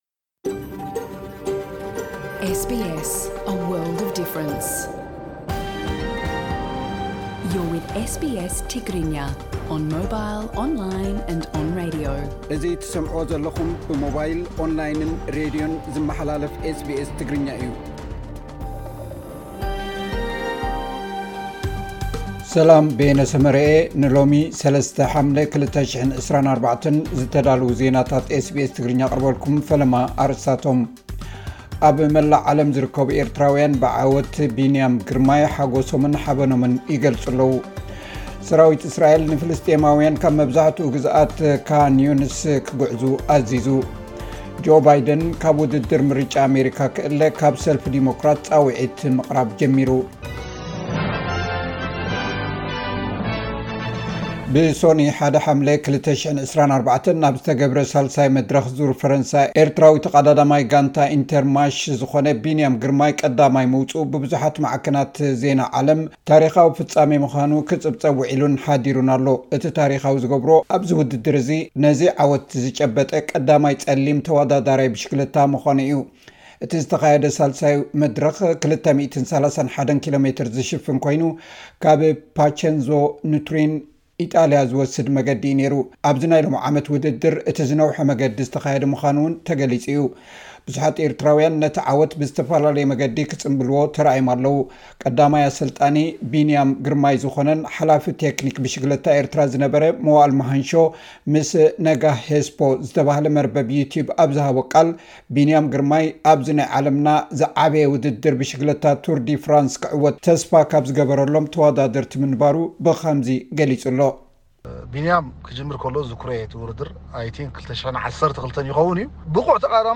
ኣብ መላእ ዓለም ዝርከቡ ኤርትራዊያን ብዓወት ቢንያም ግርማይ ሓጎሶምን ሓበኖምን ይገልጹ ኣለዉ። (ሓጸርቲ ዜናታት 03 ሓምለ 2024)